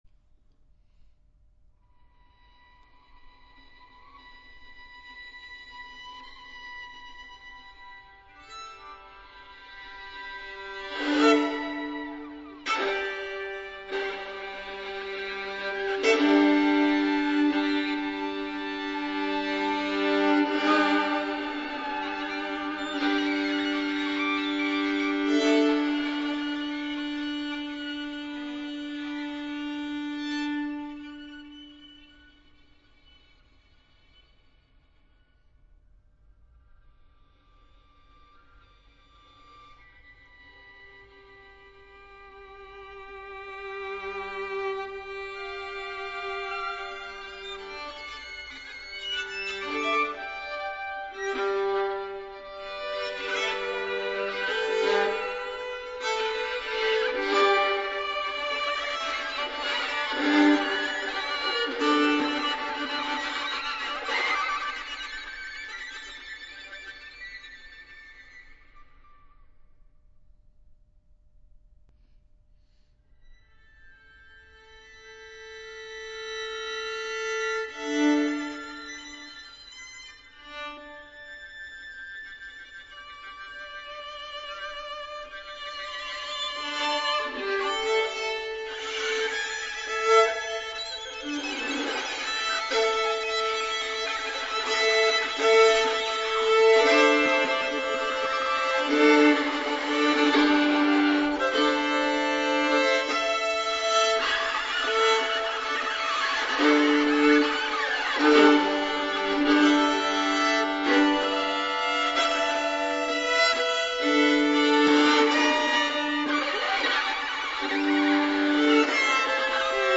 -Violins
Outtakes-recorded 15 1 14 St Michael’s Highgate